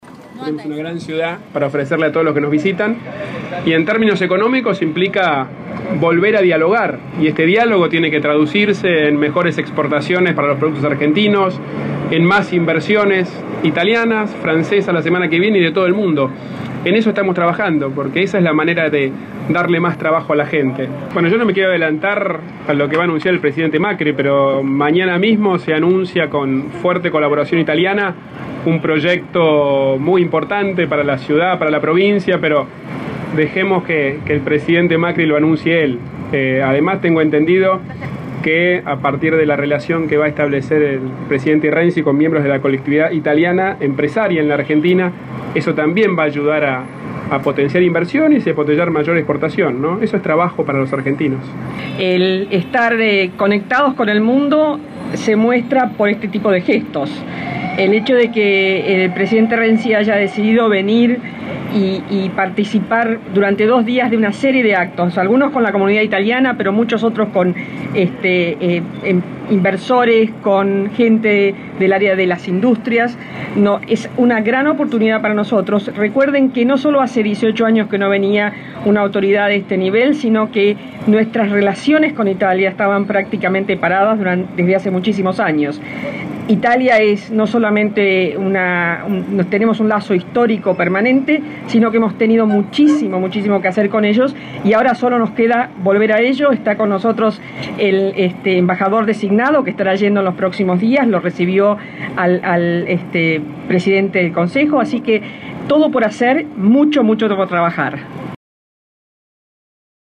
La ceremonia que se realizó ante el Monumento al General San Martín, donde ambos mandatarios colocaron una ofrenda floral.